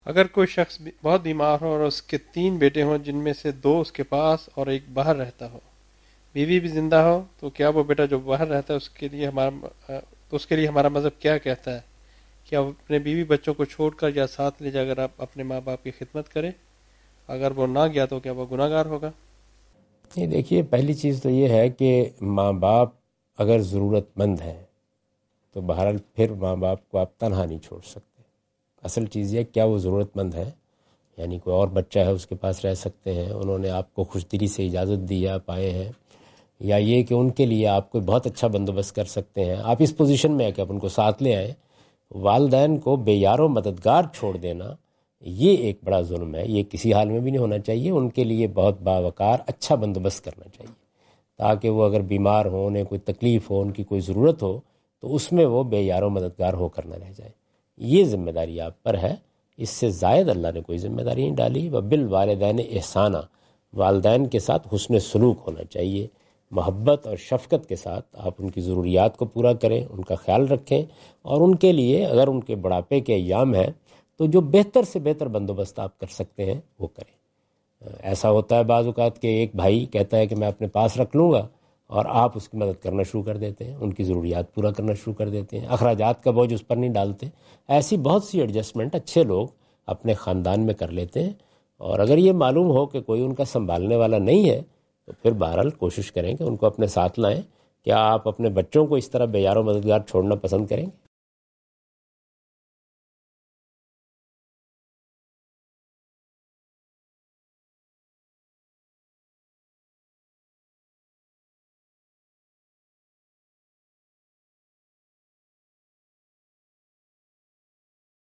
Category: English Subtitled / Questions_Answers /
Javed Ahmad Ghamidi answer the question about "Looking After One's Parents" during his Australia visit on 11th October 2015.
جاوید احمد غامدی اپنے دورہ آسٹریلیا کے دوران ایڈیلیڈ میں "والدین کی خدمت کرنا" سے متعلق ایک سوال کا جواب دے رہے ہیں۔